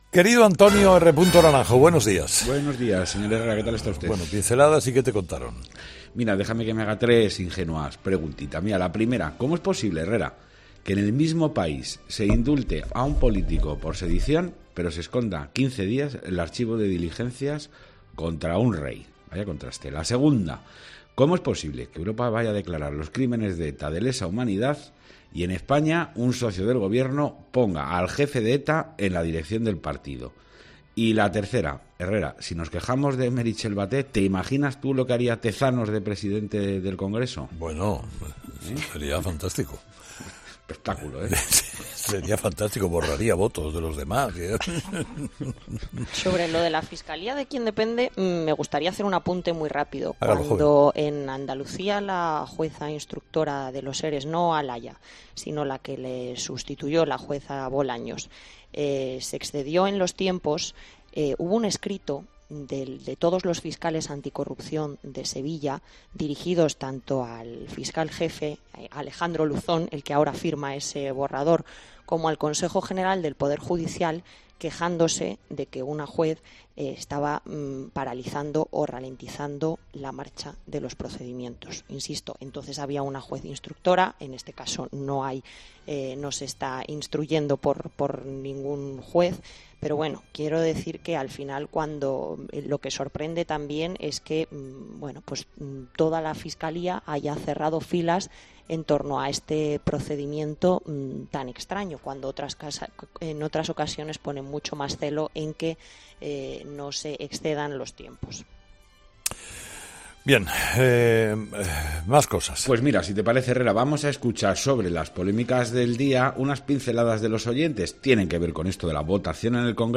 AUDIO: Los oyentes, de nuevo, protagonistas en 'Herrera en COPE' con su particular tertulia.